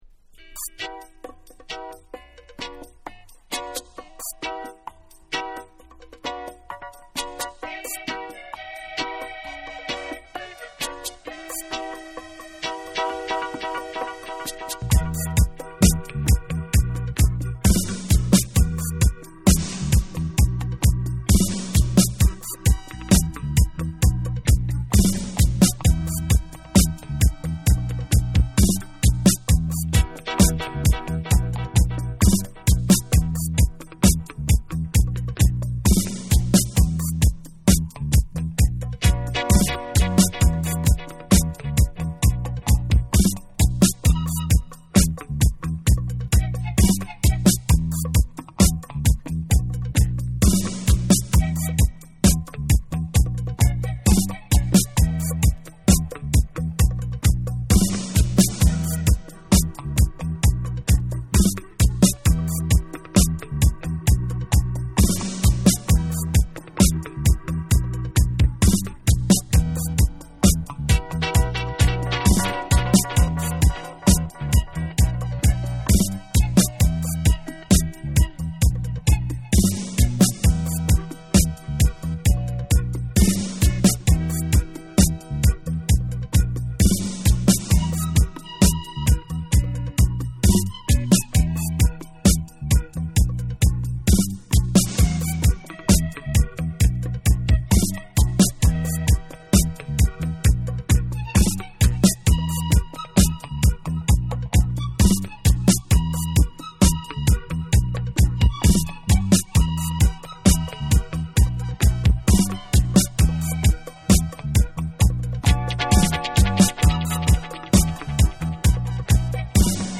UKラヴァーズを代表する歌姫
キメ細かく洗練されたメロウな音色に優しいメロディーが溶け込んだラバーズ・ロック人気曲
REGGAE & DUB / LOVERS